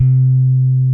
C3 1 F.BASS.wav